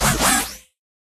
Cri de Farfurex dans Pokémon HOME.